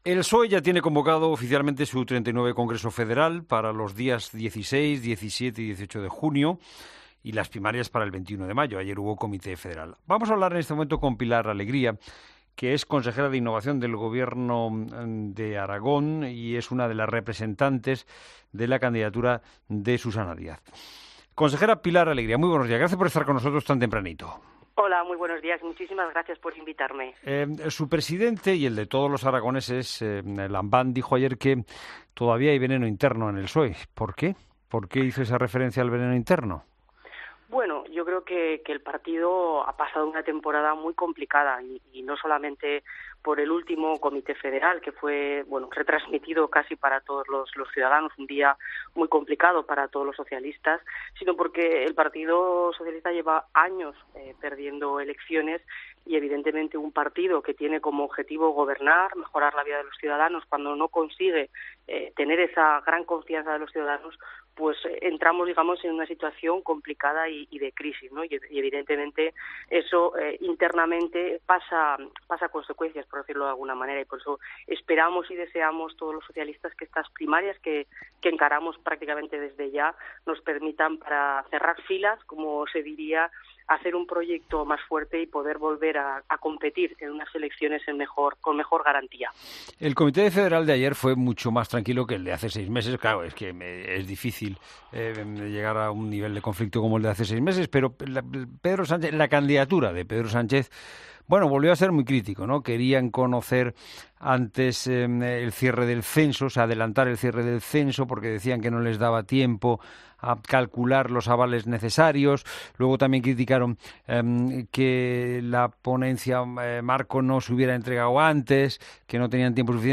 Pilar Alegría, consejera del Gobierno de Aragón, en La Mañana Fin de Semana
Entrevista política